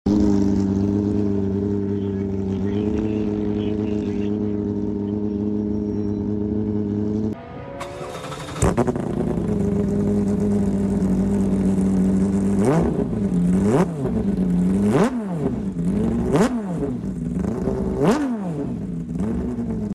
GT3 cup car sound check sound effects free download